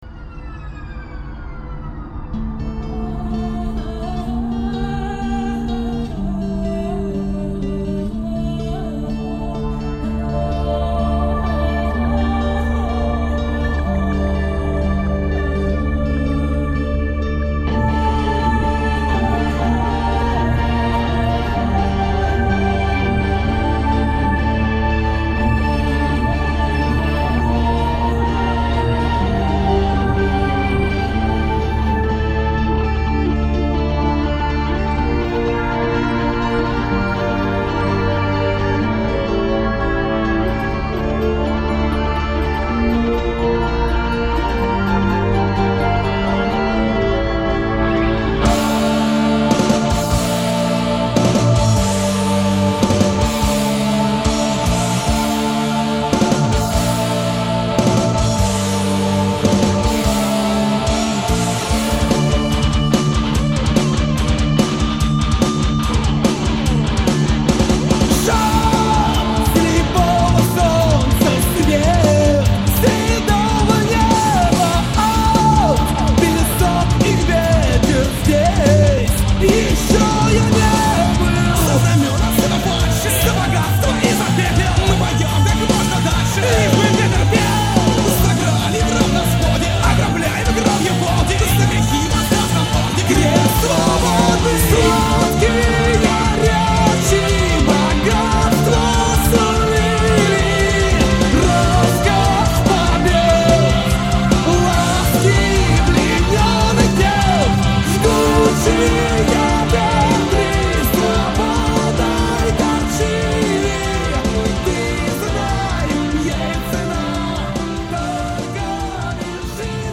(Даже музыка к крестовым походам написана современниками.